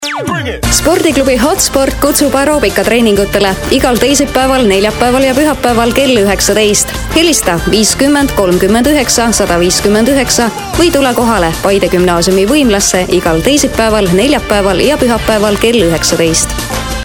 Sprecherin estnisch für TV / Rundfunk / Industrie.
Sprechprobe: Werbung (Muttersprache):
Professionell voice over artist from Estonia.